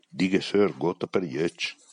10 frasi in dialetto su Santa Lucia